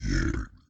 snd_zombiedie.ogg